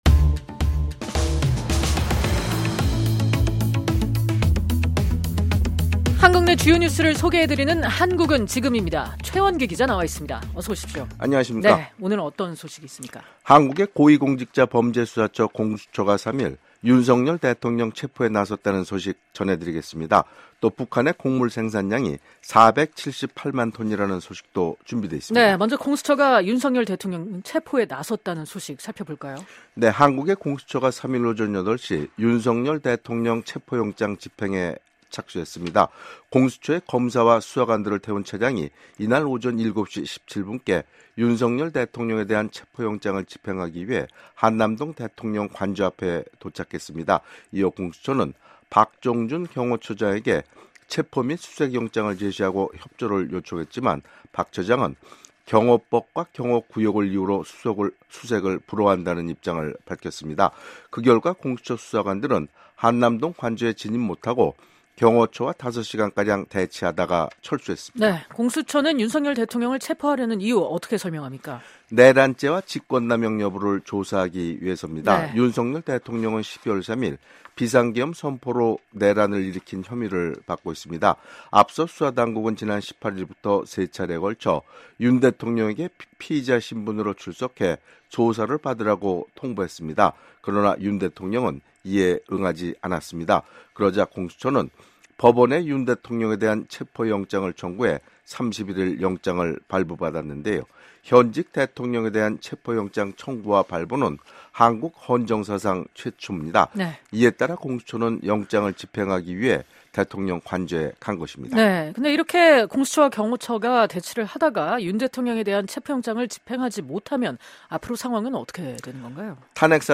한국 내 주요 뉴스를 소개해 드리는 ‘한국은 지금’입니다.